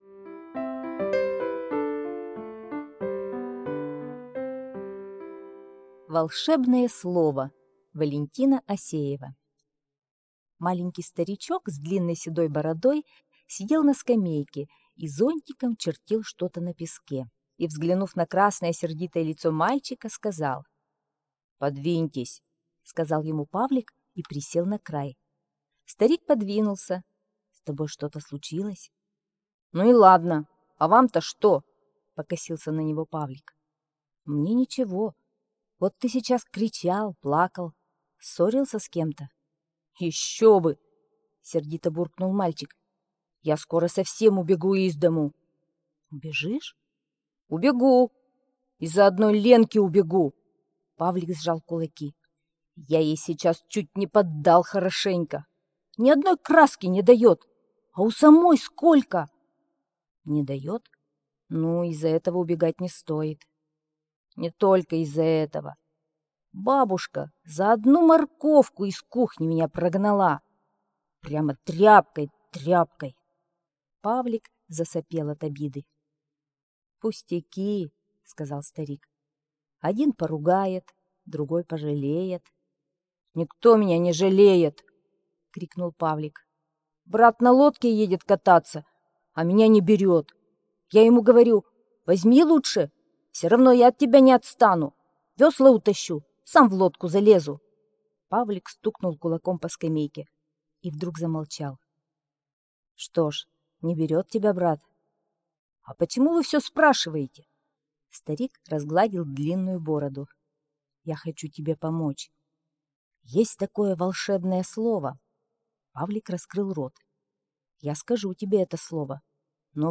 Волшебное слово – Осеева В.А. (аудиоверсия)